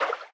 Sfx_creature_symbiote_swim_fast_07.ogg